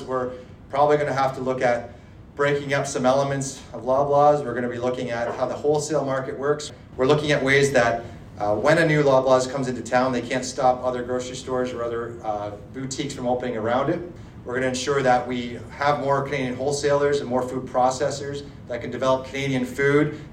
Williams was the guest speaker at Wednesday morning’s Belleville Chamber of Commerce breakfast held at Sans Souci in the Downtown District.